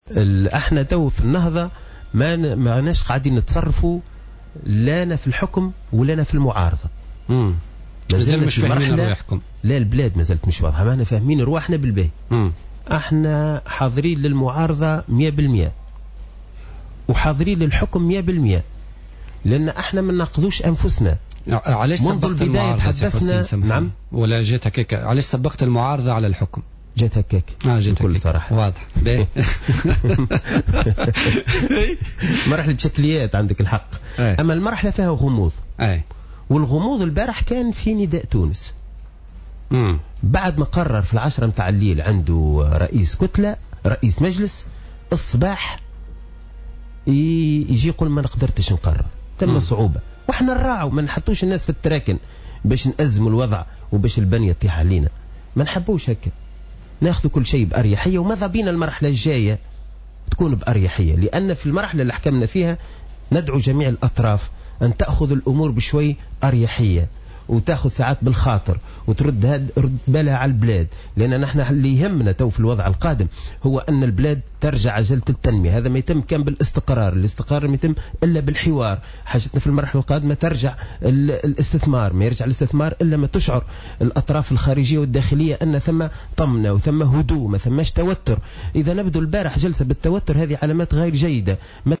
قال القيادي في حركة النهضة حسين الجزيري في تصريح للجوهرة أف أم اليوم الاربعاء إن الحركة لا تعتبر نفسها حاليا لا في السلطة ولا في المعارضة في انتظار تبلور الصورة على الساحة السياسية.